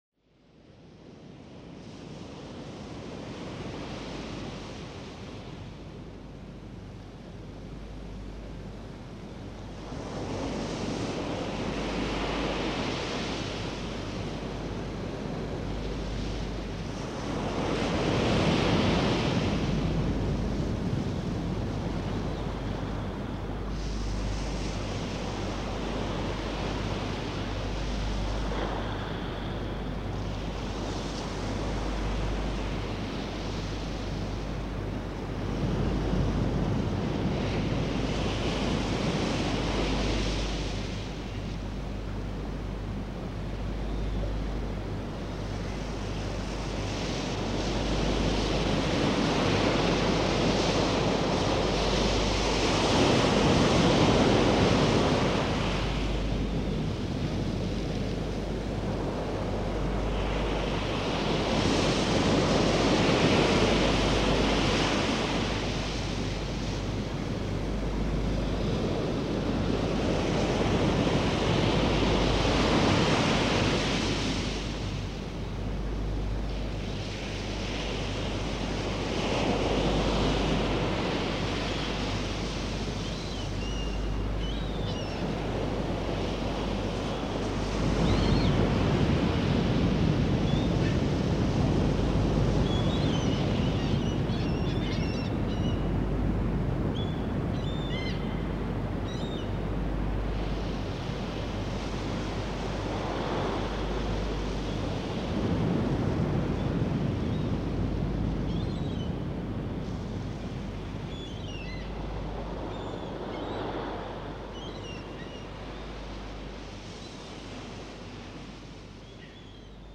No verbal. 46 minutos.